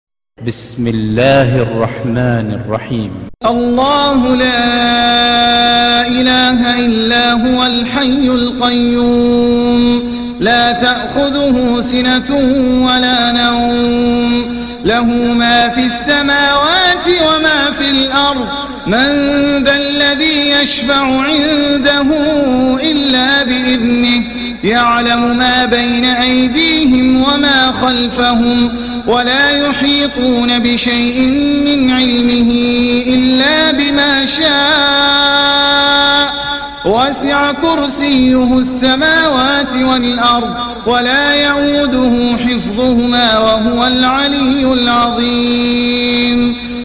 نص آية الكرسي... بصوت رائع جاهزة للتحميل mp3